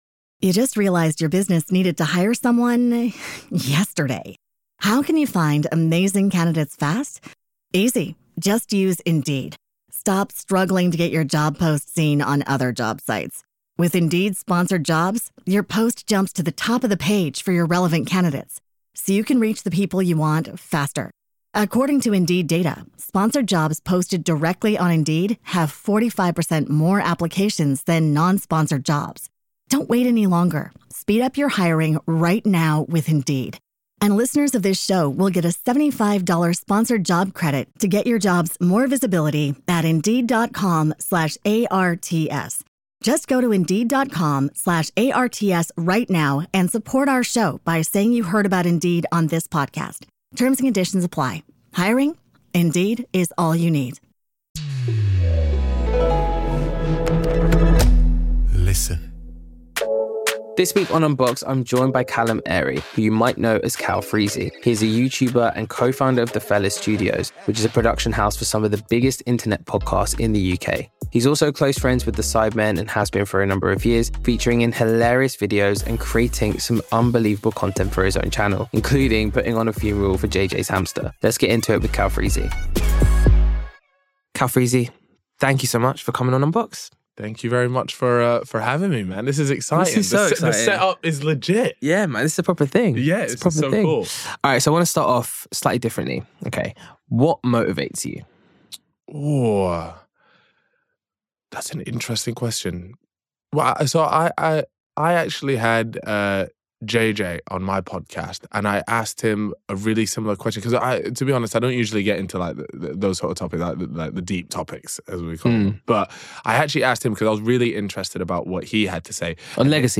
With over 4 million subscribers, Calfreezy has made a name for himself with entertaining and outrageous content, but in this candid conversation, he reveals why he doesn't view his channel as a success and what his ambitions are for the future.